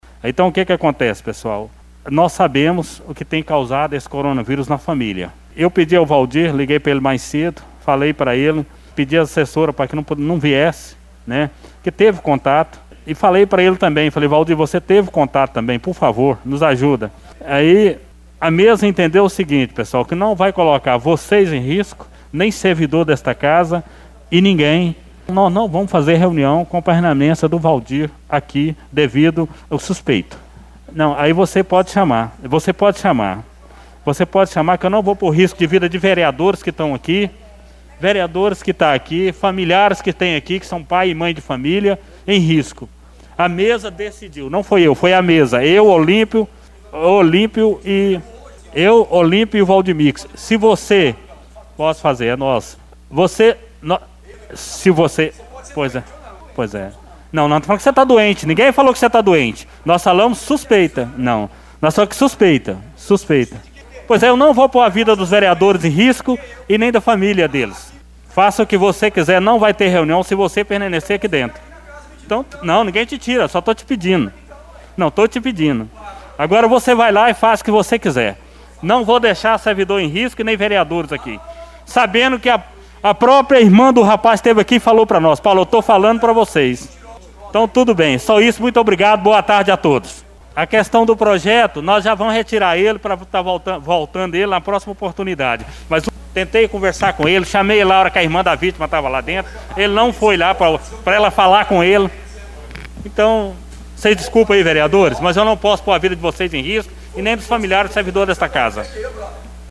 Ao ouvir que a reunião seria suspensa, Valdir Porto tentou se explicar argumentando que não estava doente, e nesse momento houve um princípio de discussão. O Presidente, disse em bom tom, “ninguém disse que você estar doente”, pediu desculpas aos demais membros e encerrou a reunião.